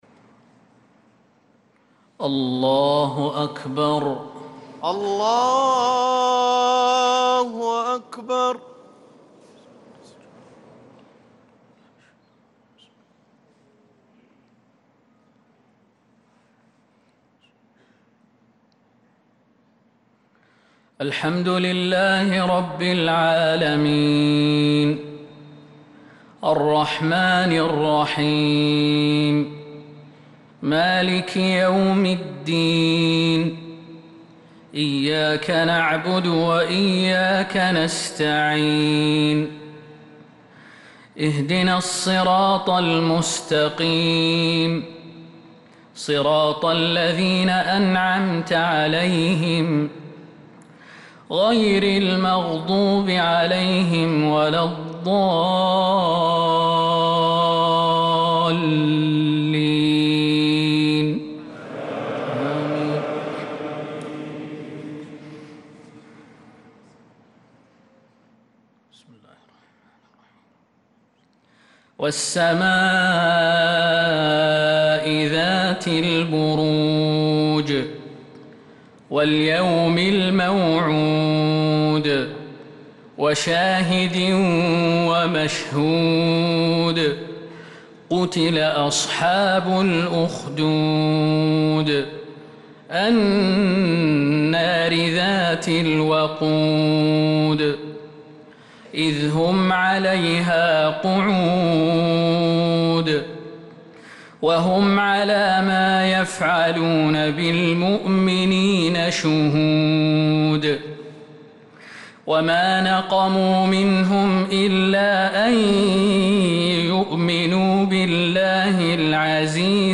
صلاة العشاء للقارئ خالد المهنا 25 ذو القعدة 1445 هـ
تِلَاوَات الْحَرَمَيْن .